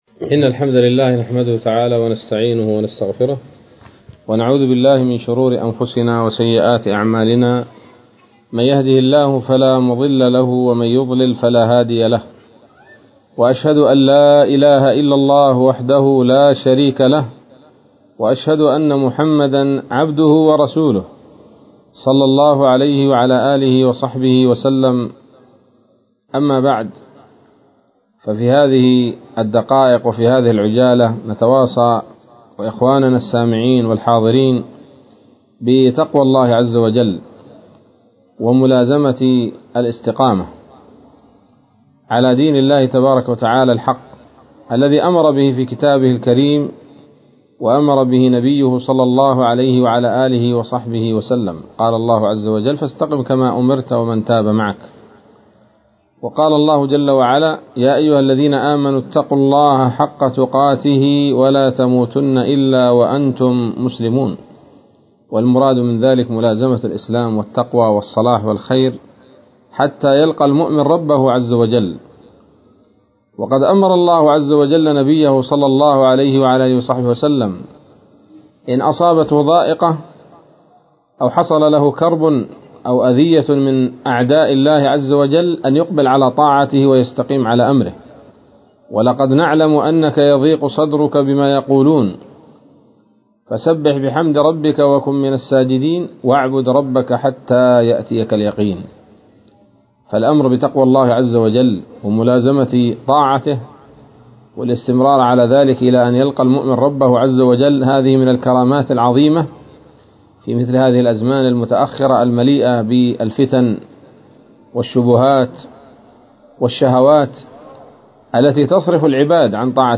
كلمة قيمة بعنوان: (( نصيحة لإخواننا في مدينة نصر من أرض القاهرة )) ليلة الأحد 24 ذو القعدة 1442هـ، بمدينة القاهر